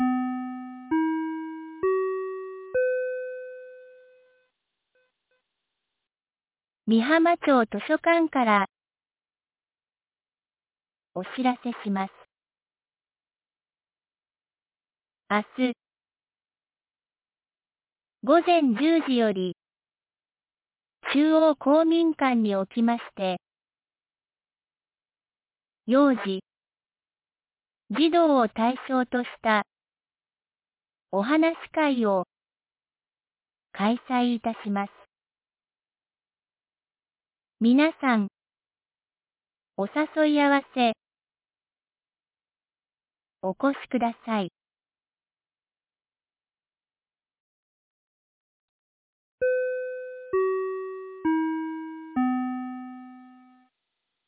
2024年04月03日 18時46分に、美浜町より全地区へ放送がありました。